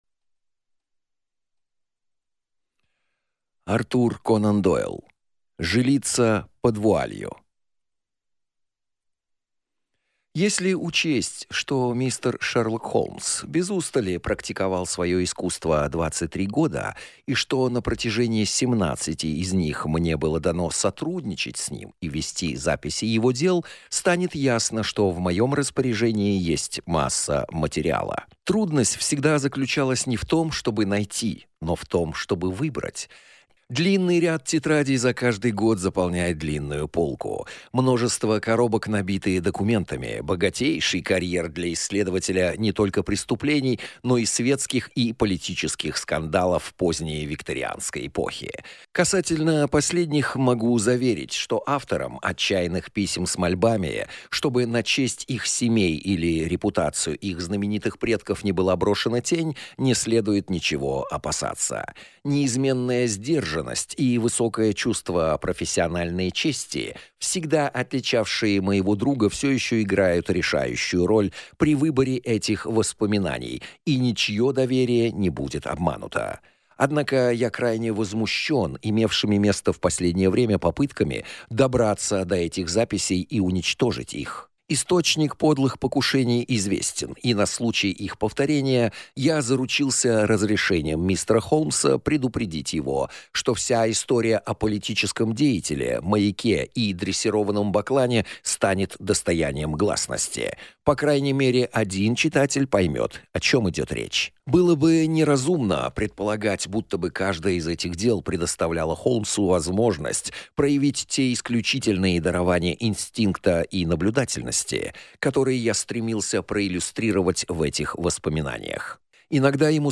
Аудиокнига Жилица под вуалью | Библиотека аудиокниг